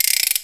HI GUILO.wav